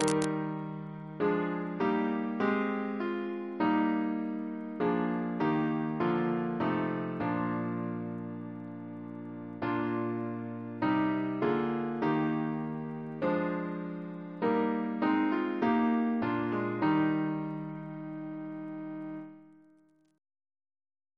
Double chant in E♭ Composer: Edward Higgins (d.1769) Reference psalters: ACB: 321; ACP: 298; PP/SNCB: 205; RSCM: 69